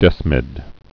(dĕsmĭd)